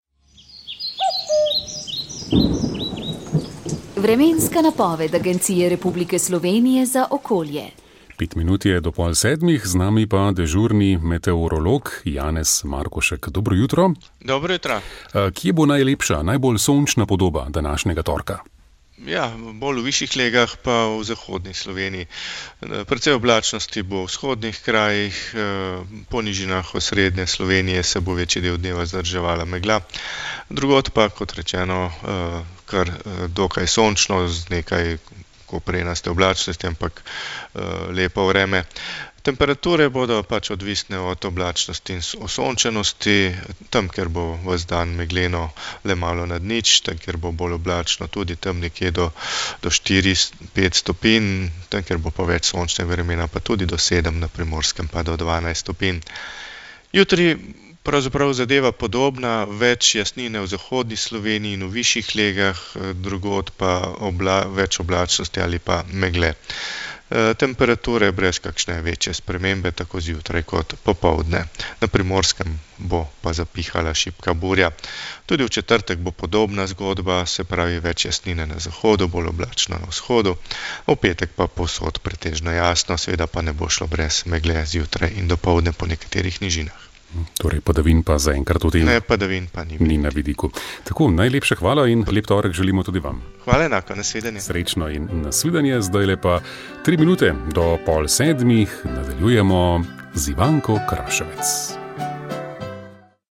Vremenska napoved 14. december 2021